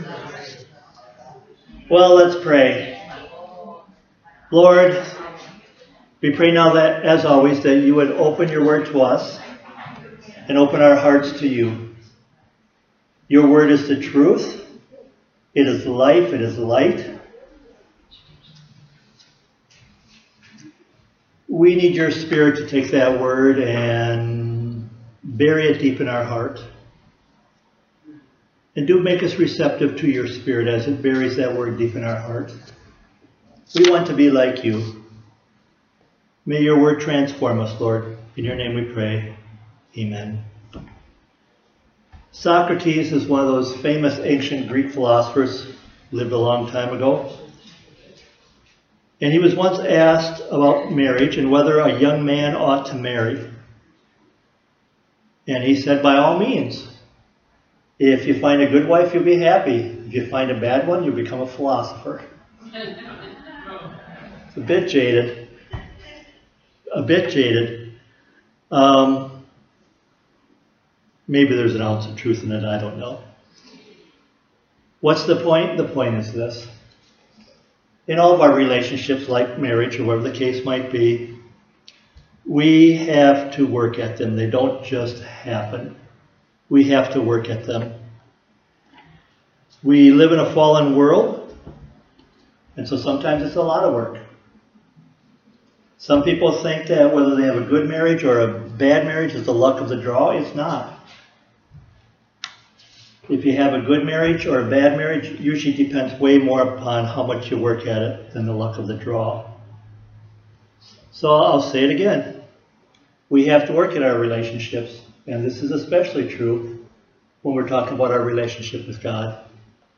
Sermons | Westview Primitive Methodist Church